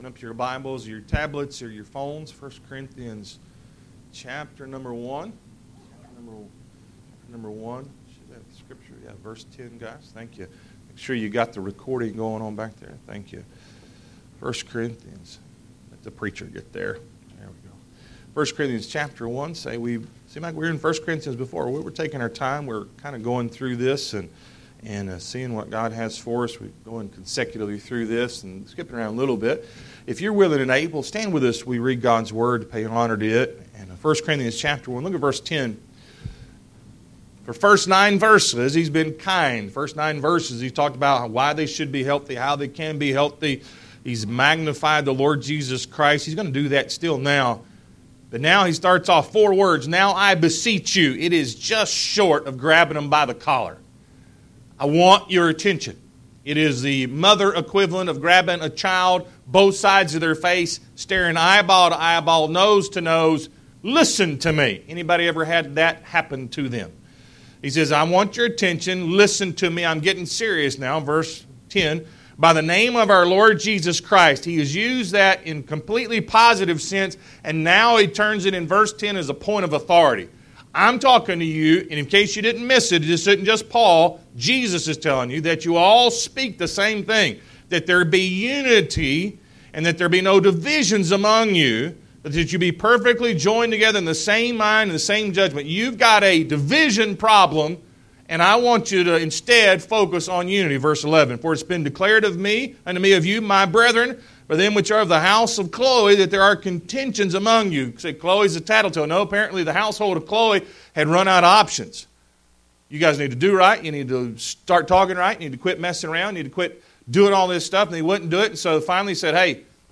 1 Corinthians 1:10–13 Service Type: Sunday AM Zero Tolerance 1 Corinthians 1:10–13 Why is God dealing with this first?